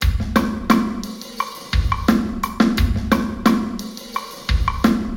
Acoustic Room Responses
All audio examples on this webpage are binaural examples.
Big Hall
binaural_sdm_big_hall_0deg.wav